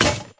break.ogg